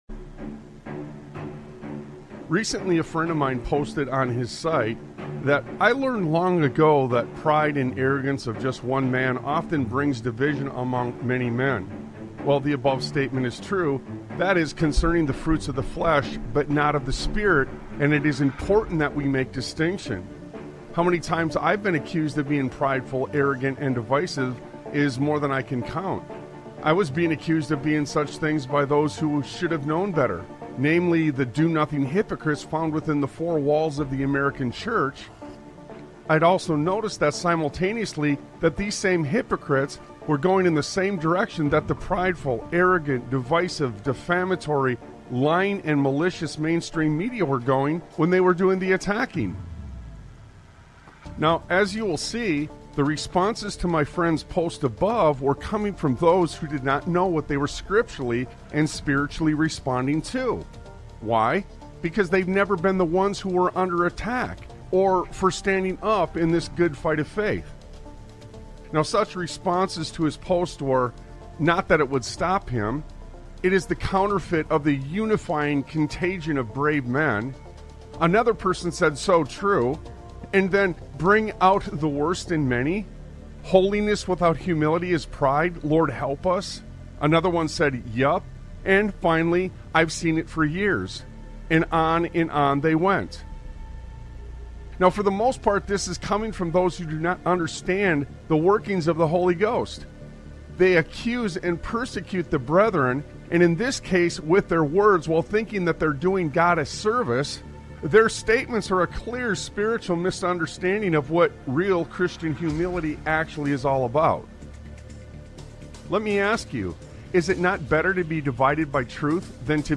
Talk Show Episode, Audio Podcast, Sons of Liberty Radio and Truth Be Told on , show guests , about truth be told, categorized as Education,History,Military,News,Politics & Government,Religion,Christianity,Society and Culture,Theory & Conspiracy